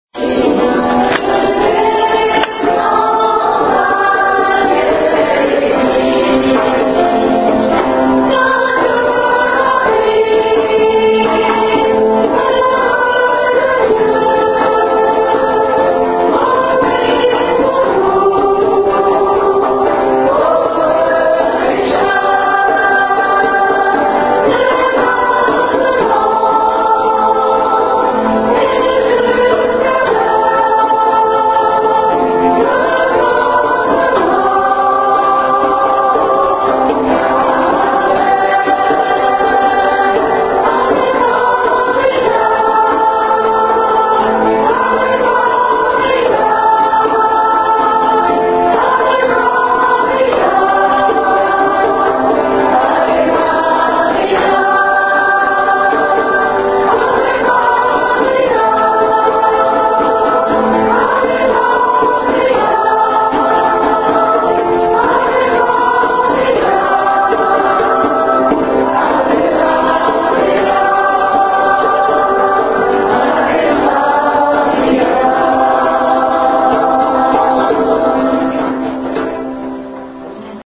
Le mercredi le 12 septembre à 20h15, à la Chapelle de l’église Ste Marie de Wittenheim a eu lieu une Veillée de louange et d’adoration au pied de la Croix Glorieuse.
À cette occasion, nous avons chanté l’Ave Maria de Glorious. Pour écoutez le « live », c’est par ici :